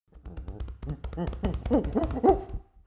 دانلود آهنگ میمون 3 از افکت صوتی انسان و موجودات زنده
جلوه های صوتی
دانلود صدای میمون 3 از ساعد نیوز با لینک مستقیم و کیفیت بالا